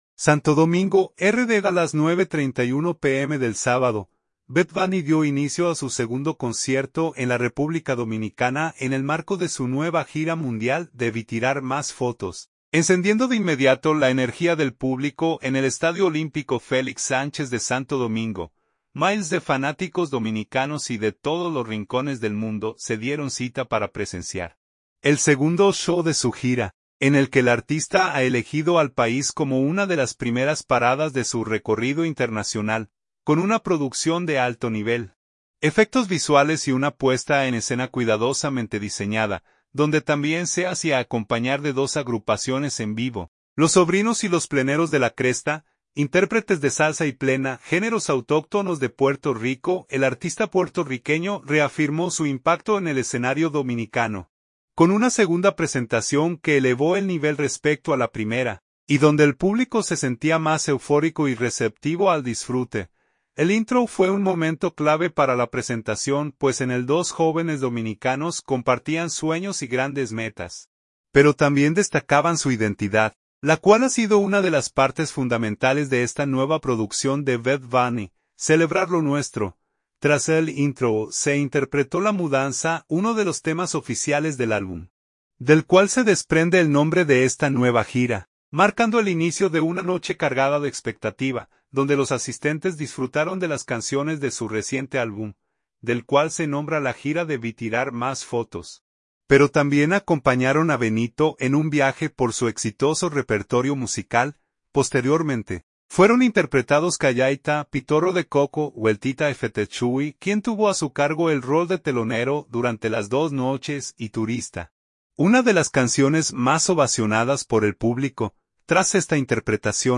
donde también se hacía acompañar de dos agrupaciones en vivo
intérpretes de salsa y plena